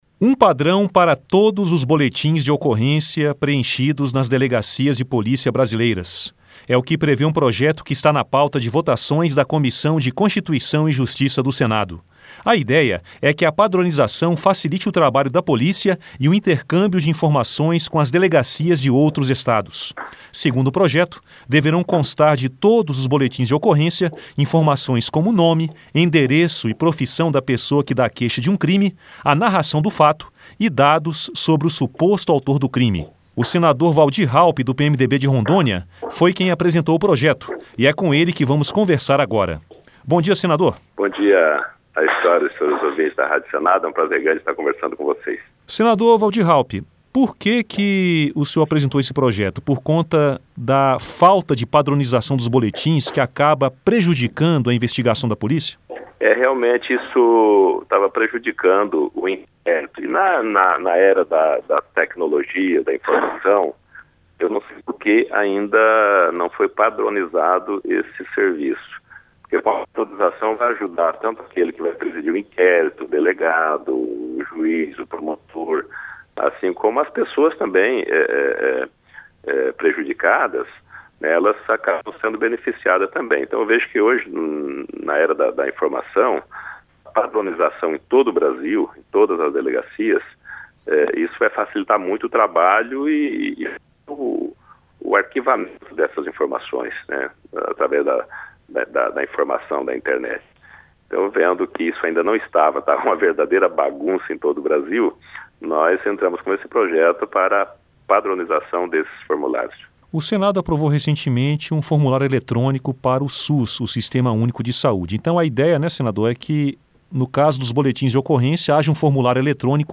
Entrevista com o senador Valdir Raupp (PMDB-RO).